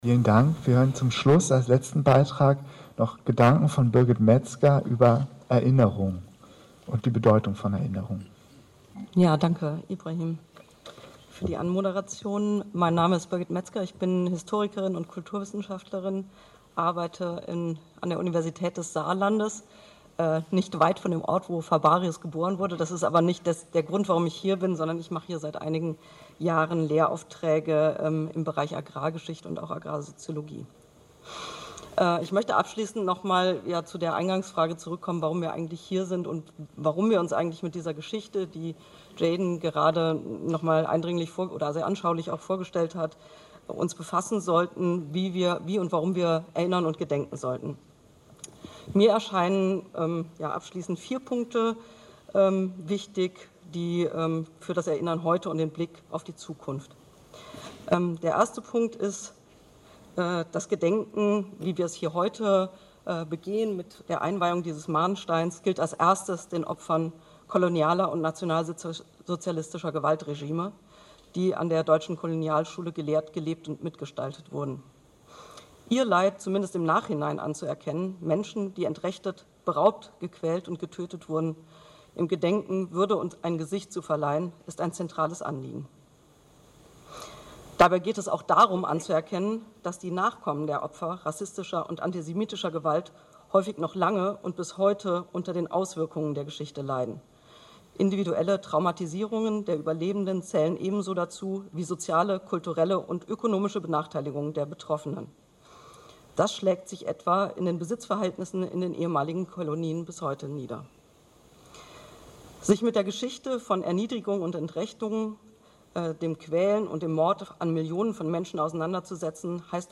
Redebeiträge: